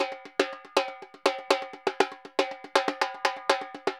Repique Candombe 120_2.wav